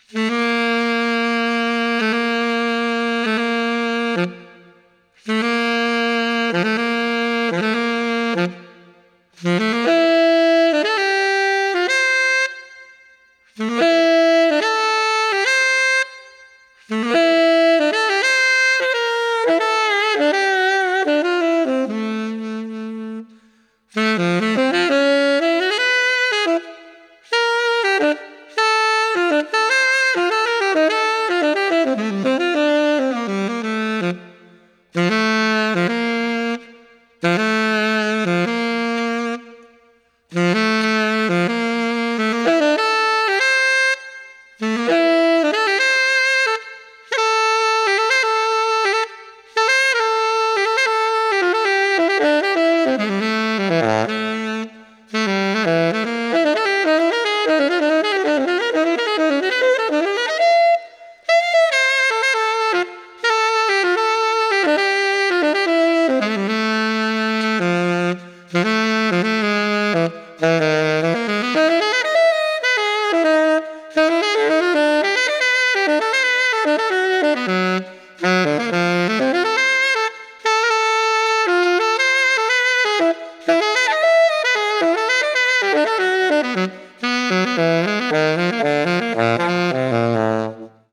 Choose a jazz note below.